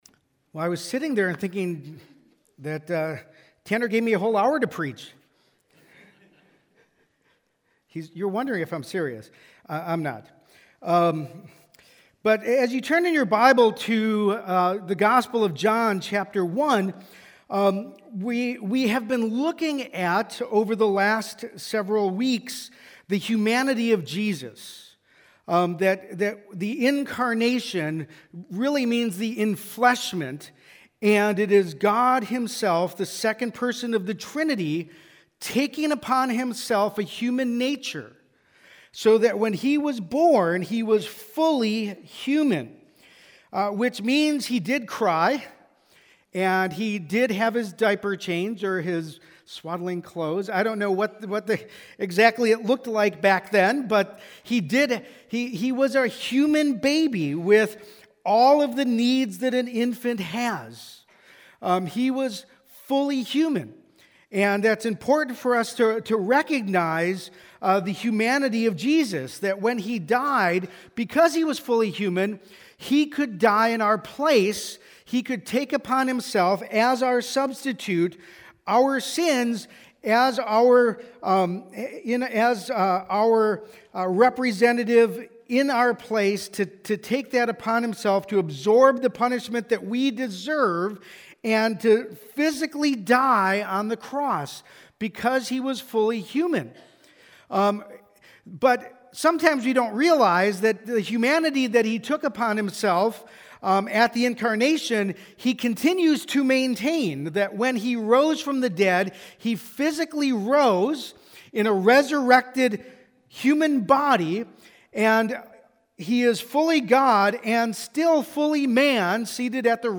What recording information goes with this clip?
Advent 2023 Passage: John 1:1-14 Service: Sunday Morning When we think of the "Christmas Message